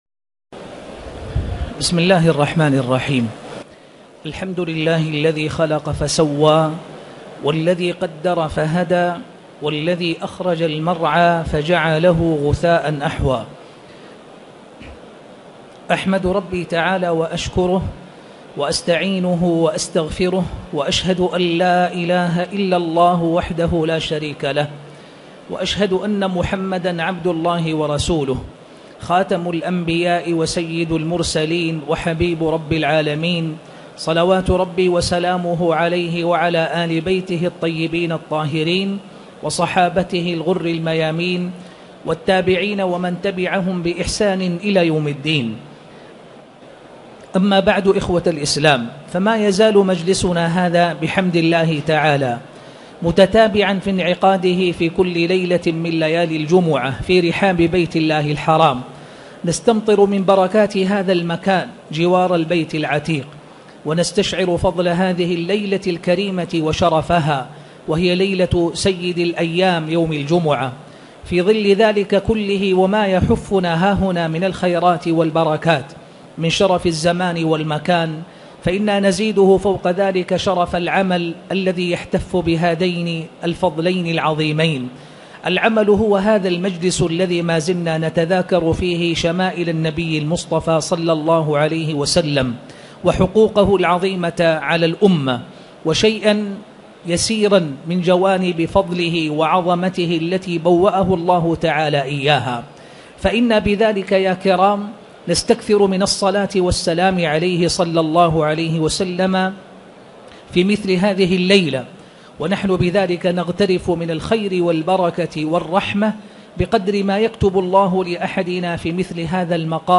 تاريخ النشر ٥ جمادى الأولى ١٤٣٨ هـ المكان: المسجد الحرام الشيخ